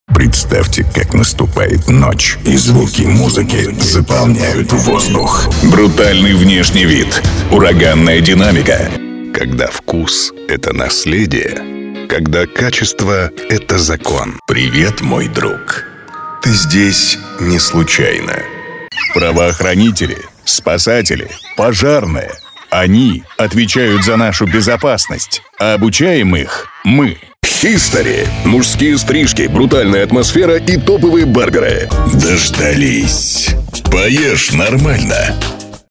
Пример звучания голоса
Муж, Рекламный ролик/Средний
Звуковая карта leyla echo, перамп dbx 376, микрофон NEUMANN TLM 103, акустическая кабина